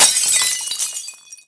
collision.wav